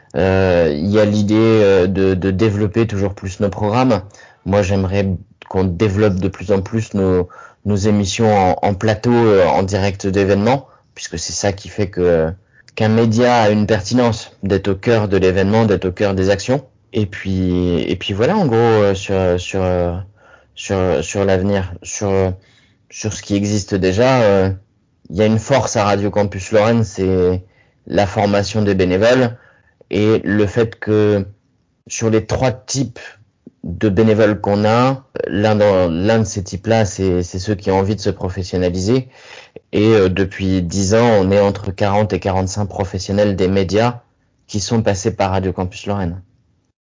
L’interview Flash